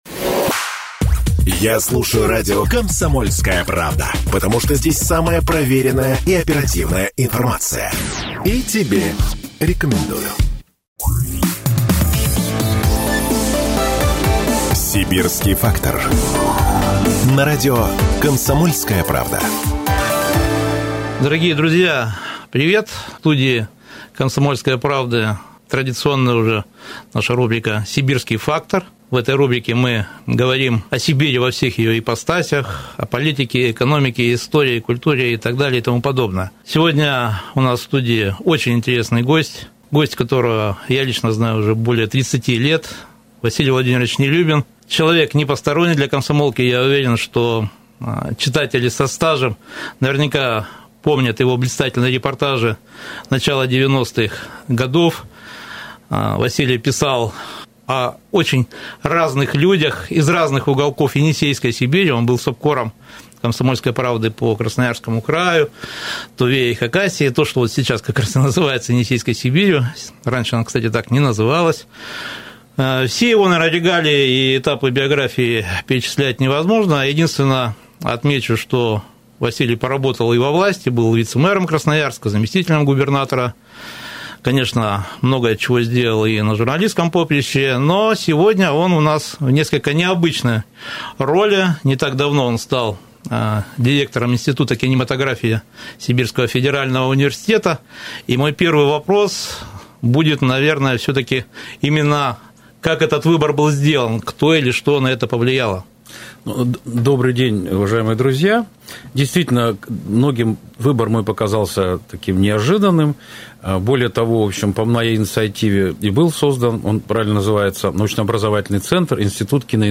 Красноярский край сегодня можно считать признанным лидером киноиндустрии в Сибири и на Дальнем Востоке. О том, кто и когда снимал кино в Енисейской Сибири, о темах и проблемах, о кадрах и перспективах развития красноярского кинематографа поговорили с экспертом постоянной рубрики в прямом эфире Радио «КП» Василием Нелюбиным — журналистом, экс-заместителем губернатора, общественным деятелем, директором института киноиндустрии СФУ.